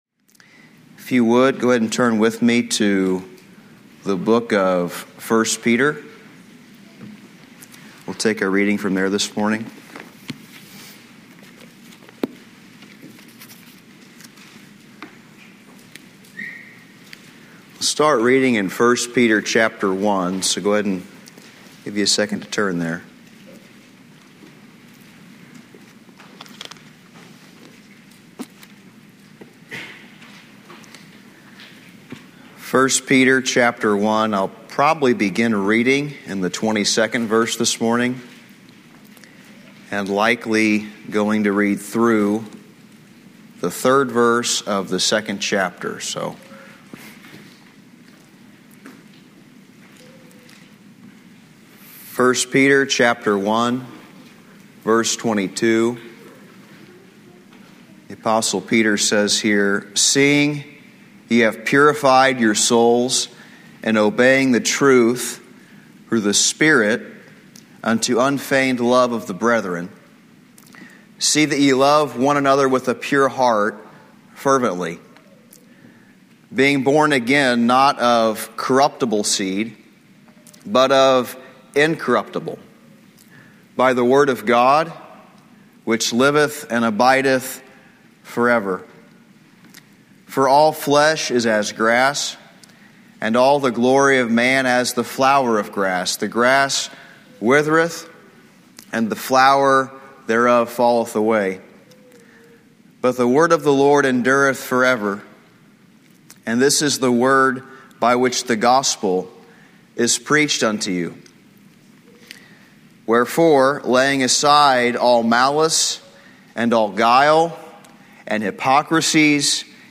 Lesson 1 from the 2009 Old Union Ministers School.